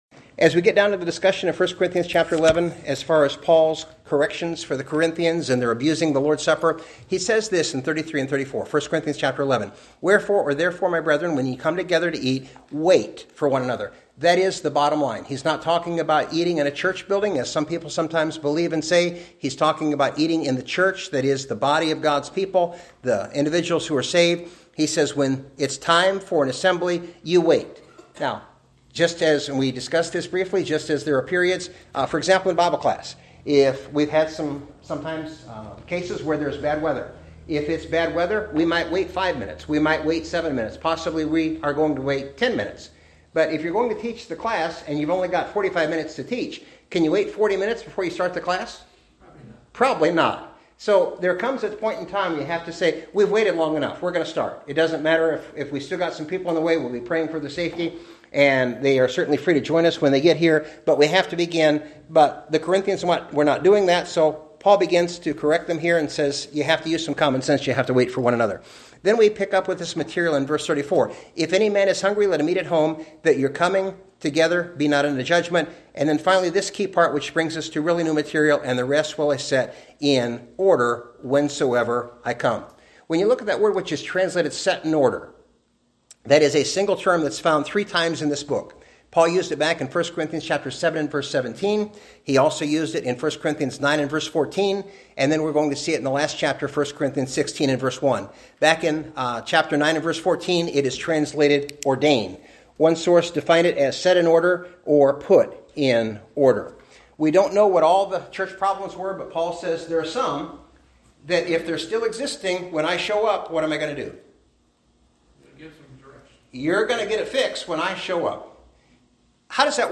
bible-study-on-worship.mp3